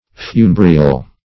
Funebrial \Fu*ne"bri*al\ (f[-u]*n[=e]"br[i^]*al), a. [L.
funebrial.mp3